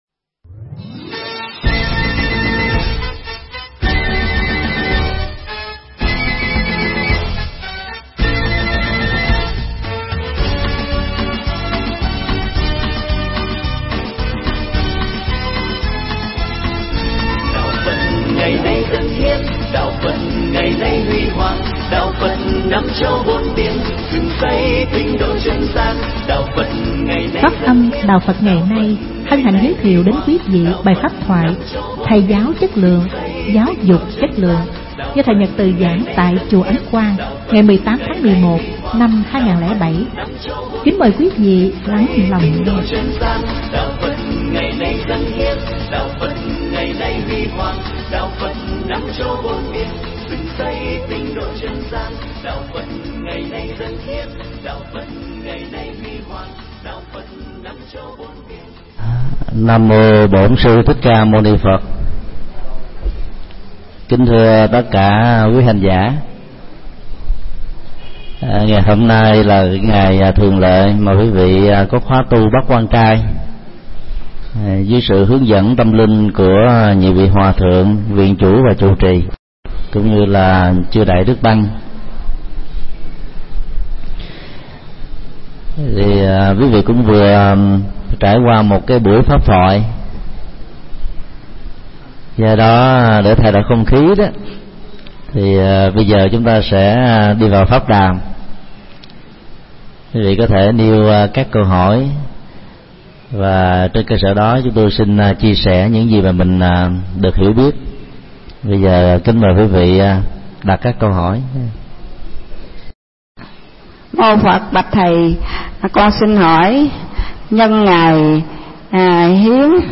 Mp3 pháp thoại Thầy giáo chất lượng
Chùa Ấn Quang